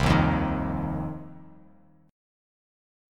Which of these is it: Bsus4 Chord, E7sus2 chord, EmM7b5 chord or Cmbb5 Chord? Cmbb5 Chord